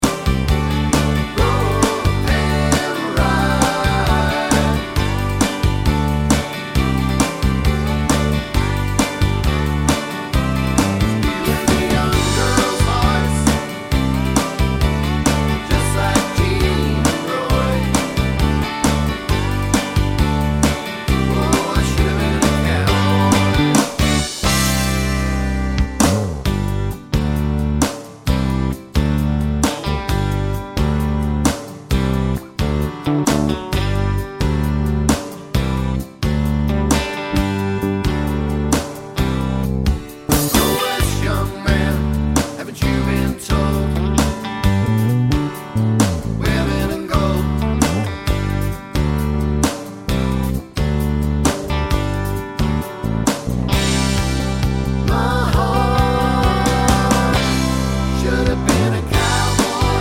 no Backing Vocals Country (Male) 3:24 Buy £1.50